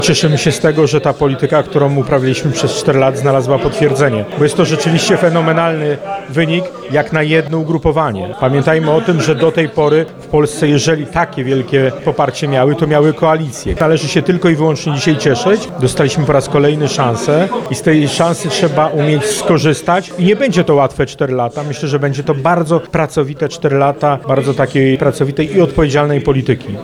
To najlepszy wynik jednej partii w wyborach parlamentarnych oraz najlepsza frekwencja od 1989 r. – mówi Andrzej Kosztowniak, poseł PiS.